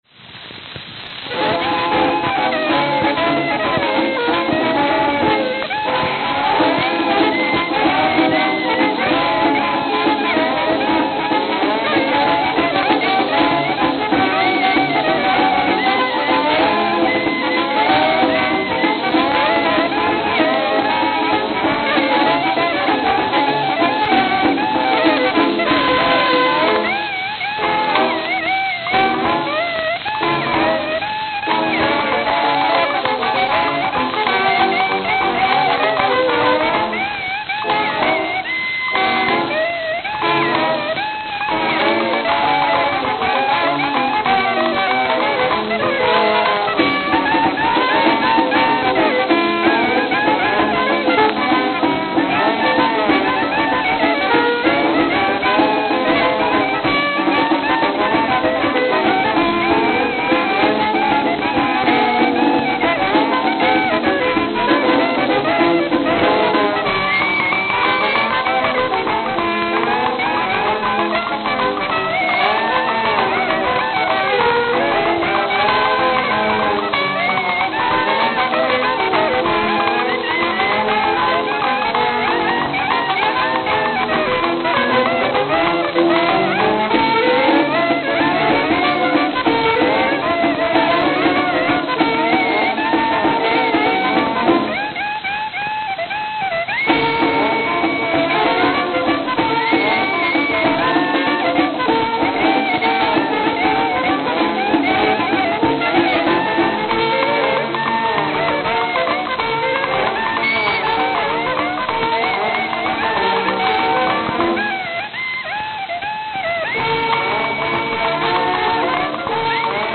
I even only use the left channel when transferring these.
Note: Very worn.